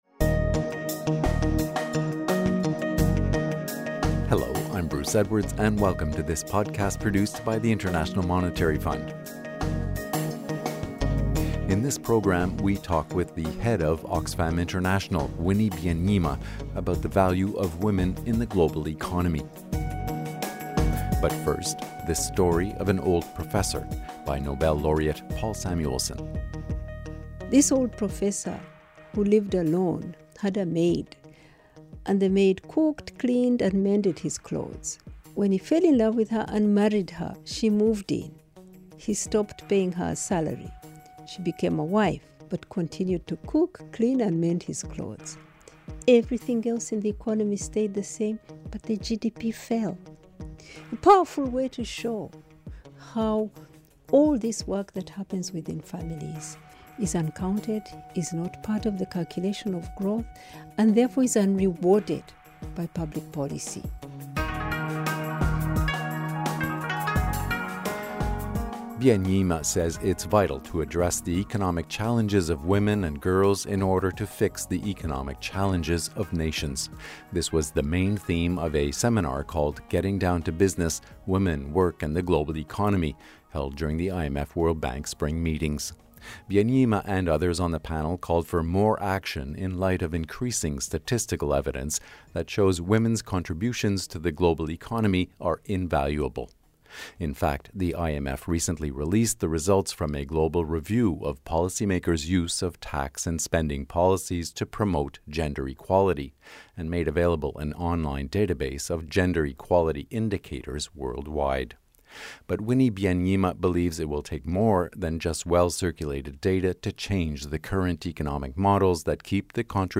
Winnie Byanyima, Executive Director of Oxfam International